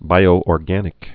(bīō-ôr-gănĭk)